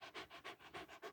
DogGasp.wav